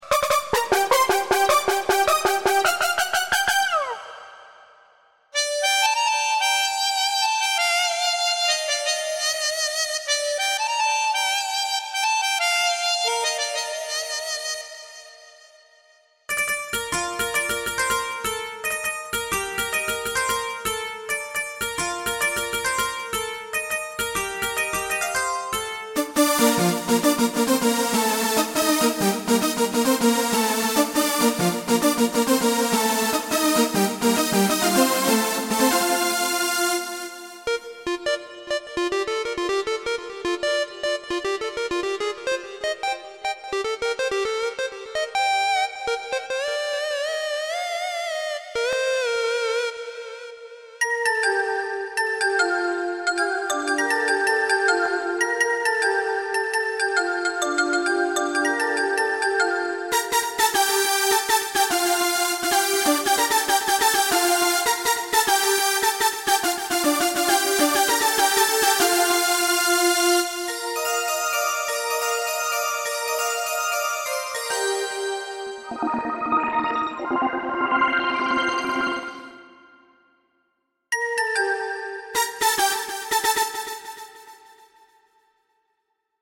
SAMPLES KUMBIA FUSION MI LAMENTO